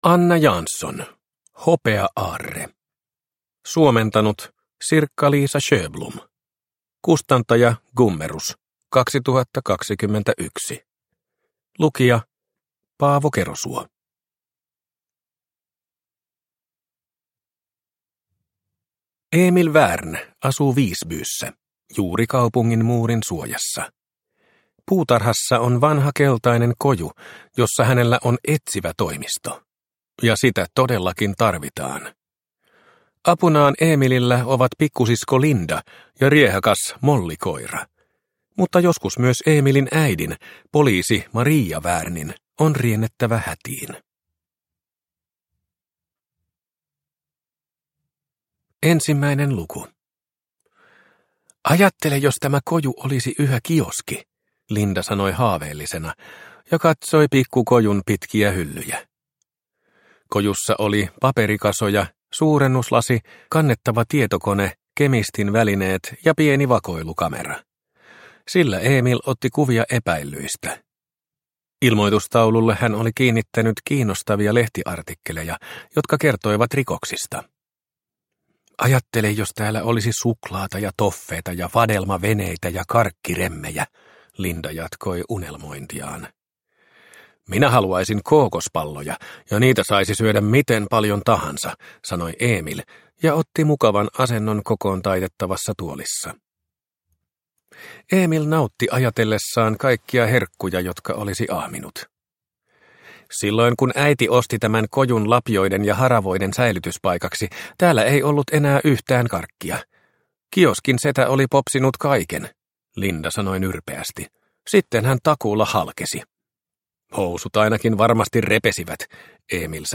Hopea-aarre – Ljudbok – Laddas ner